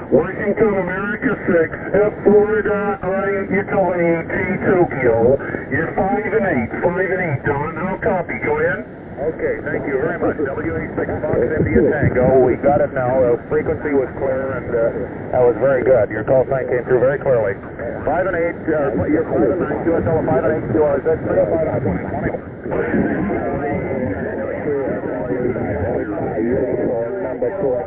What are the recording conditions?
operate from 1S1A in 1973 on Spratly Island on 20 Meter SSB from California!